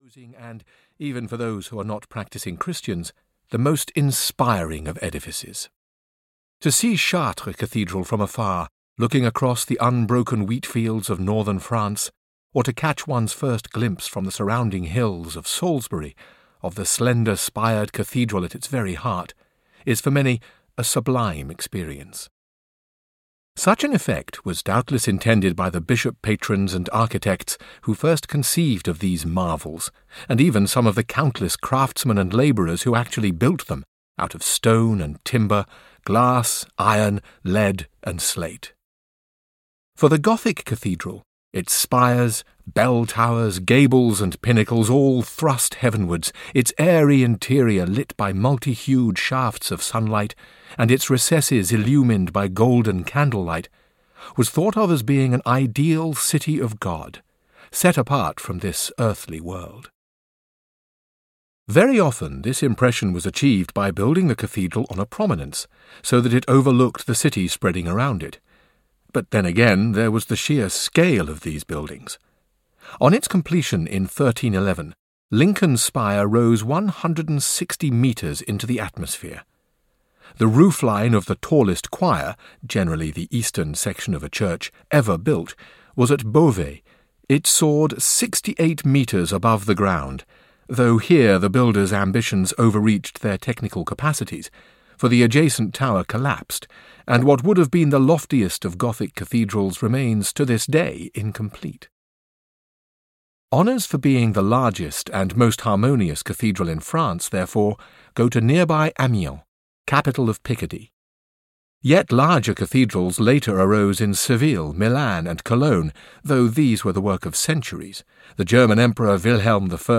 Cathedrals – In a Nutshell (EN) audiokniha
Ukázka z knihy